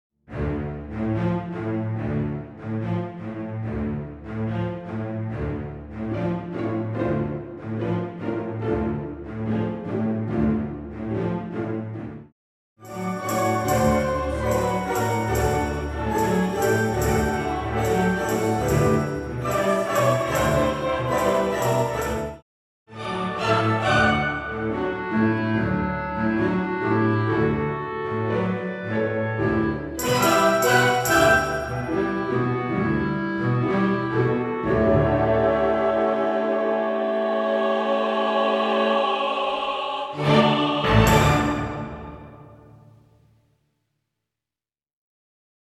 WITH CHORUS
full orchestral accompaniment